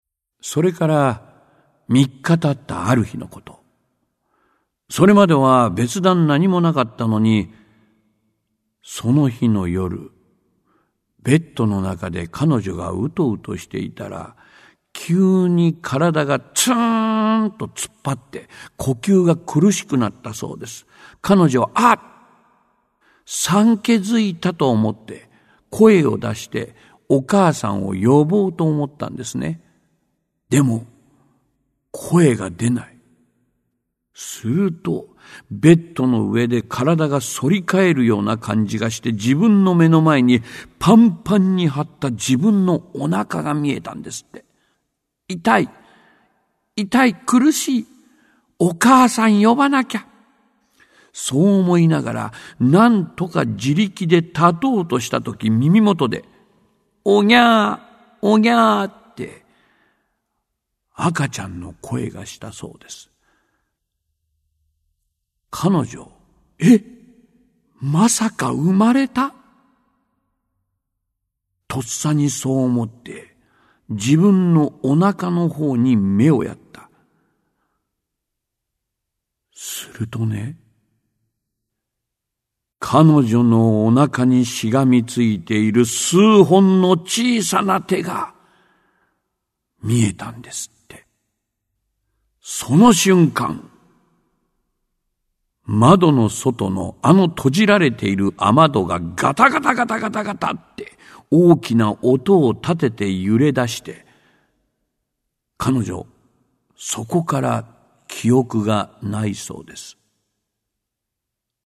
オーディオブック
ただ恐ろしい・怖いだけではなく、怪談の風情を楽しめる語りが特徴。